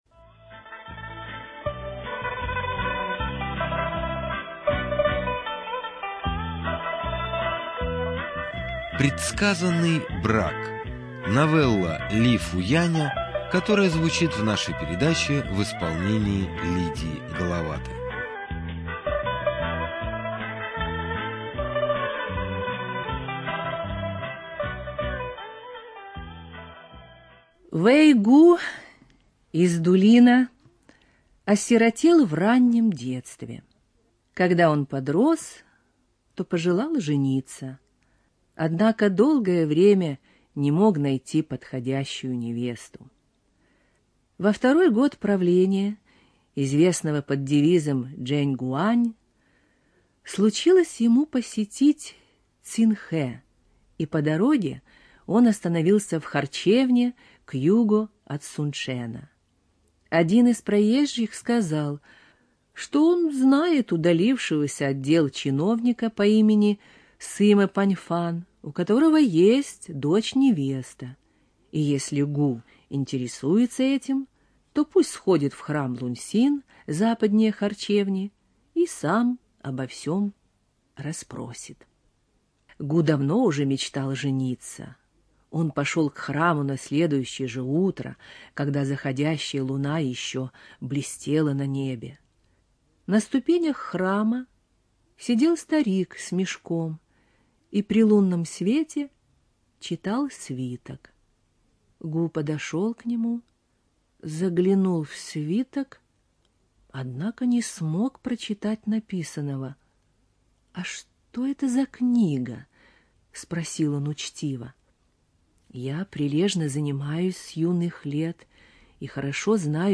Студия звукозаписиРадио 4